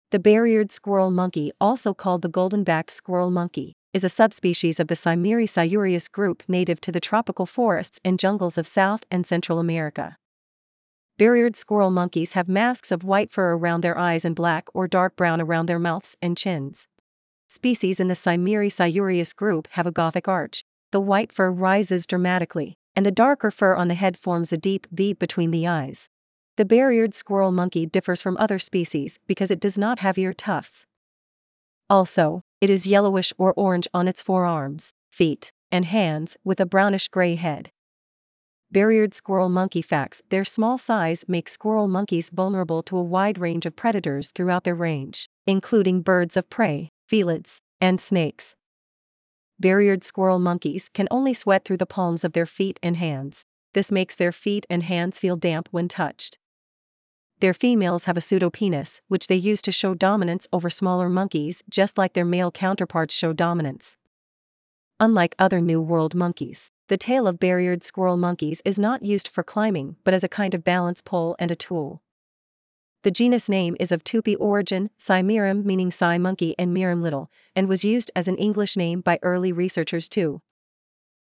Bare-eared Squirrel Monkey
Bare-eared-Squirrel-Monkey.mp3